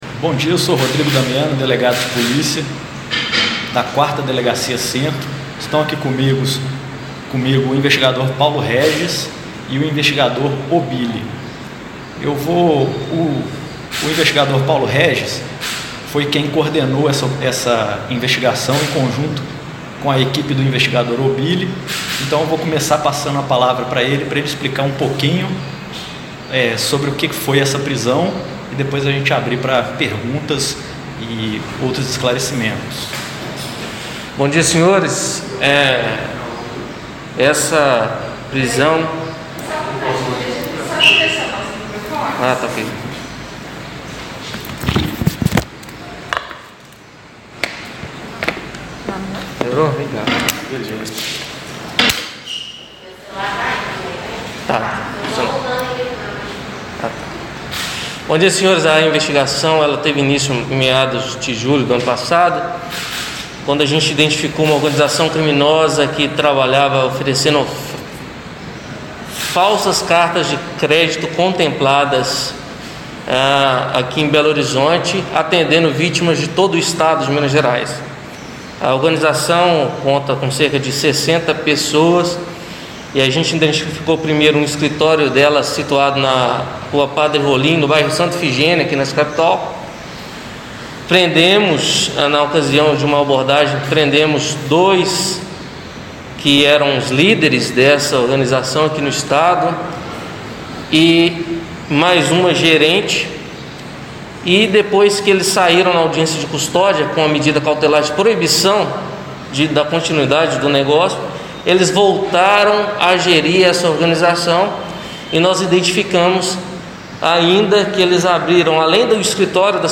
Ouça a Sonora da Coletiva
Coletiva-Policia-Civil.mp3